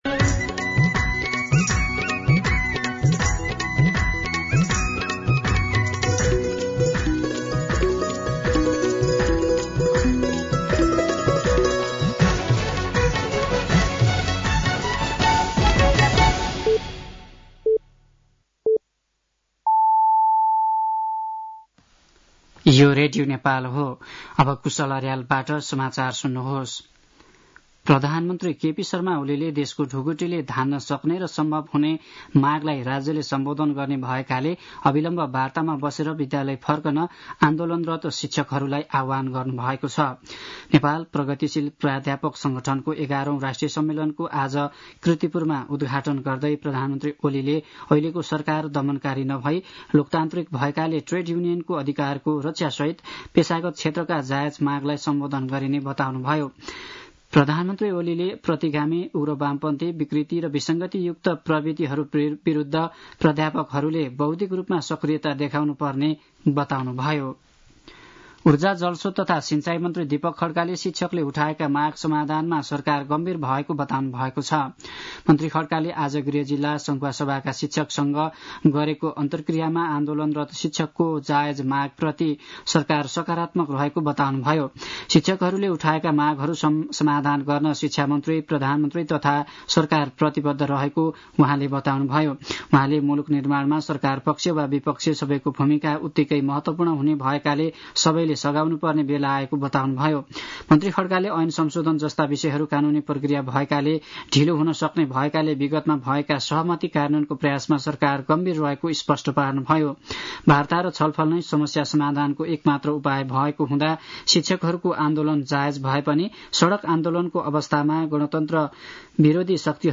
साँझ ५ बजेको नेपाली समाचार : ८ वैशाख , २०८२
5.-pm-nepali-news-1.mp3